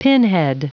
Prononciation du mot pinhead en anglais (fichier audio)
Prononciation du mot : pinhead